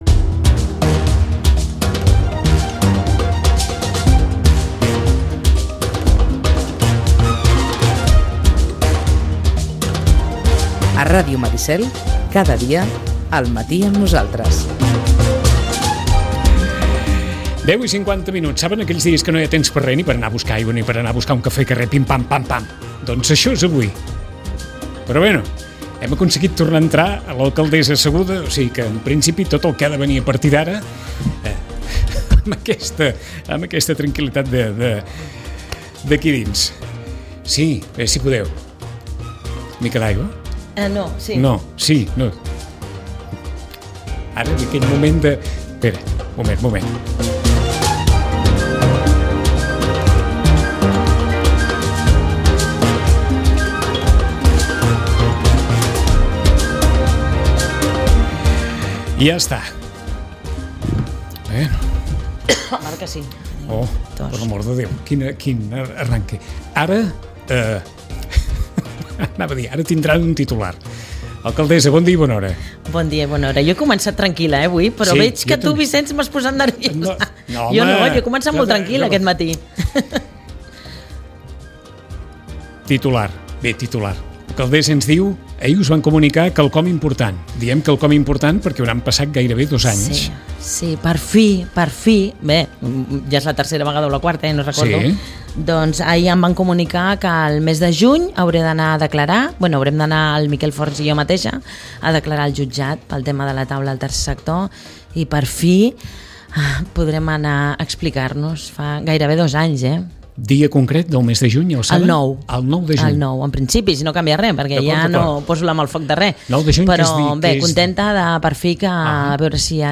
La conversa ha començat per aquí, amb un desig molt evident per part de l’alcaldessa d’explicar-se, i ha seguit per la ressaca del ple extraordinari sobre l’estat del municipi, la compra de tres pisos de la promoció de La Caixa a la Plana Est, la planificació a l’entorn de les promocions públiques de vivenda, i l’assumpte de la nova escola-institut i el posicionament del departament d’educació de la Generalitat.